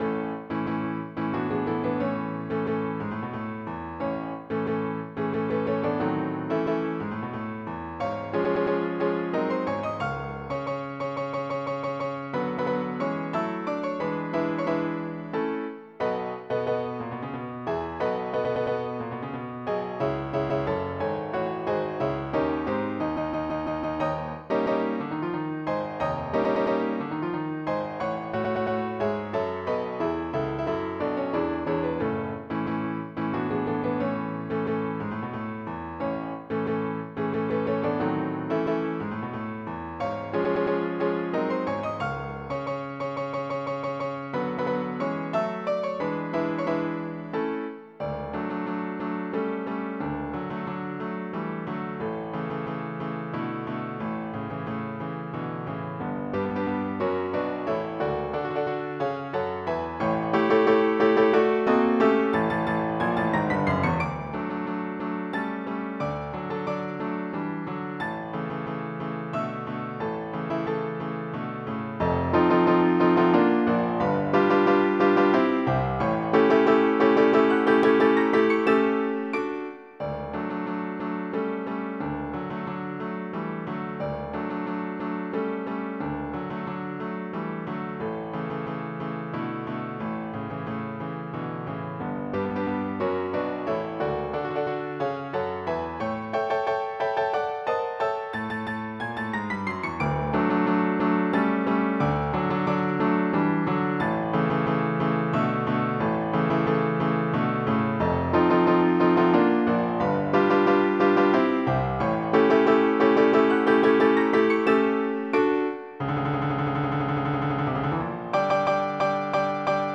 MIDI Music File
polonase.mp3